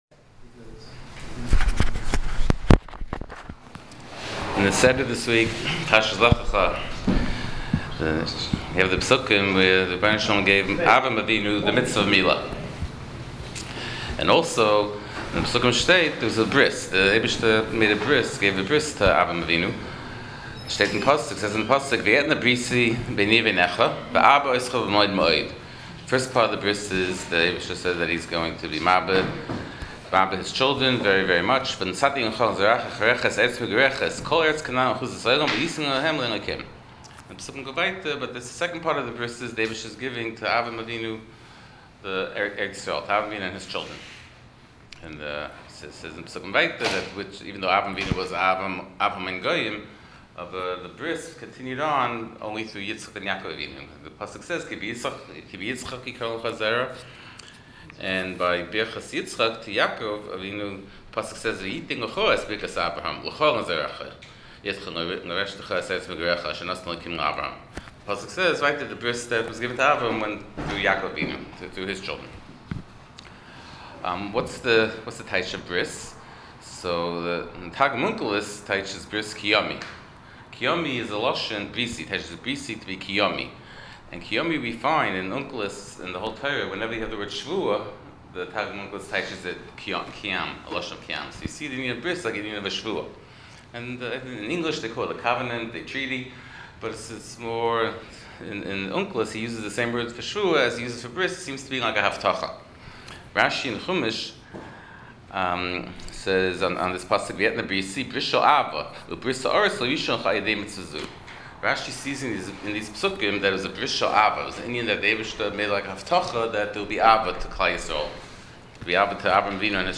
Shiur provided courtesy of Madison Art Shop.